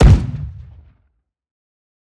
Kick GunAction 1.wav